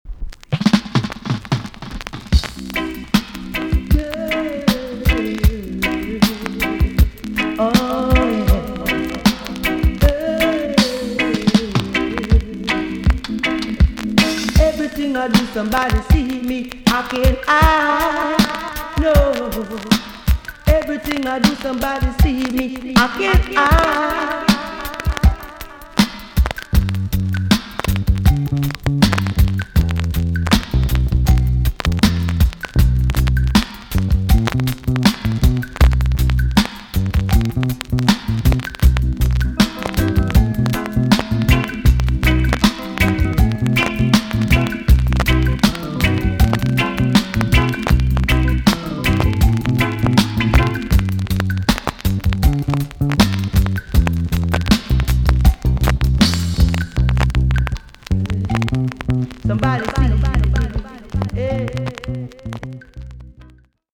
B.SIDE Version
VG ok 全体的に軽いチリノイズが入ります。